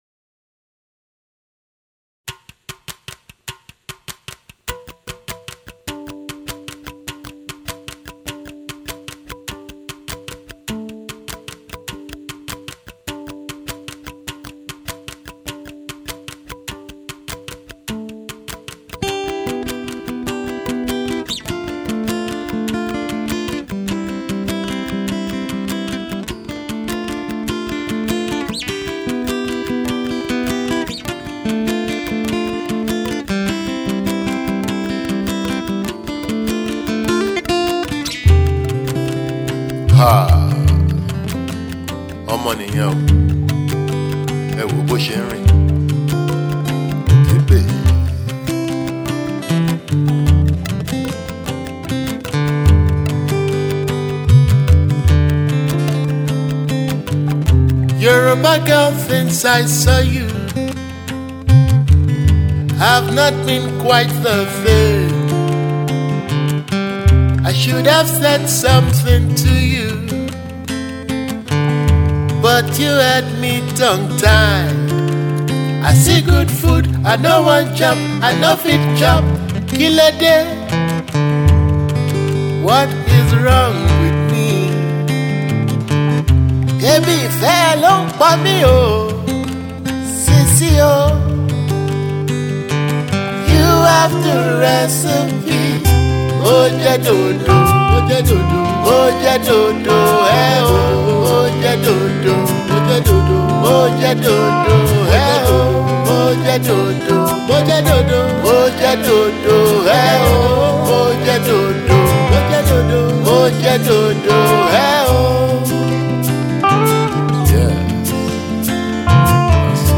“Yoruba Soulful Roots” music
The songs catchy and witty Yoruba chorus